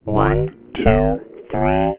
ring modulation" (17k).
demo-ringmod.au